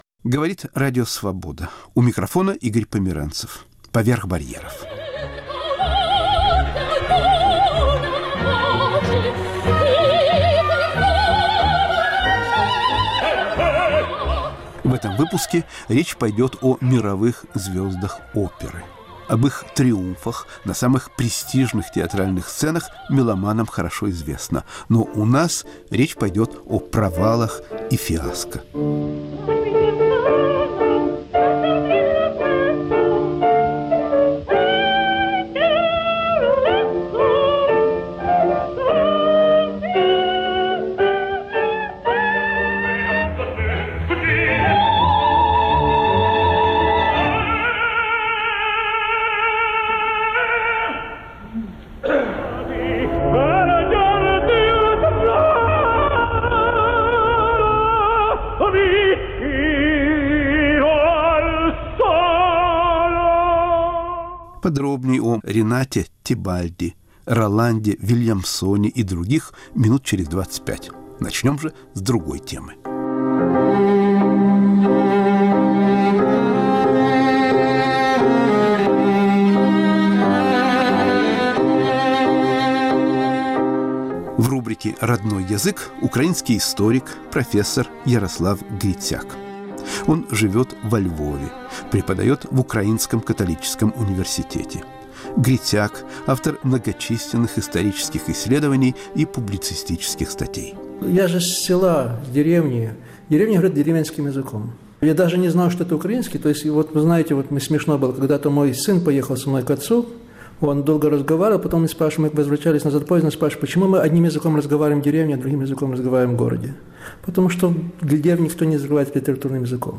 Беседа с историком Ярославом Грицаком.** Провалы и фиаско оперных звёзд.